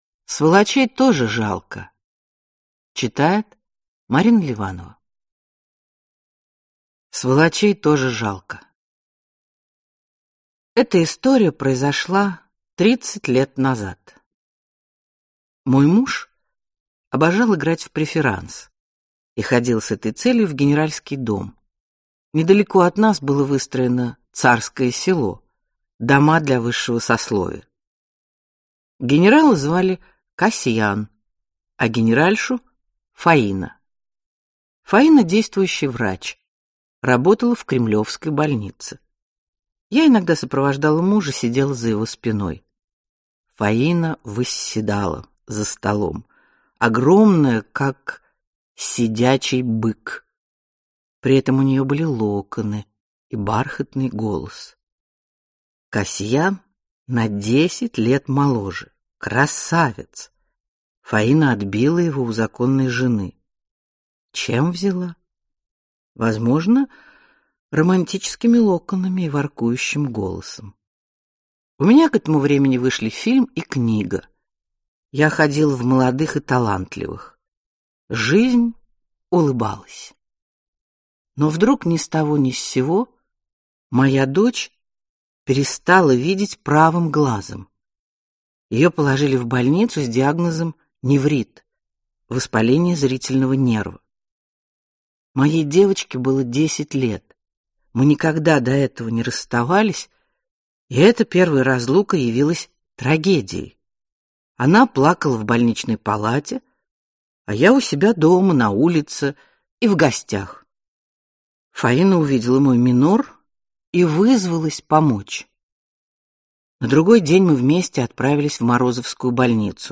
Аудиокнига Сволочей тоже жалко (сборник) | Библиотека аудиокниг